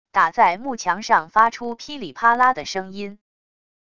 打在木墙上发出噼里啪啦的声音wav音频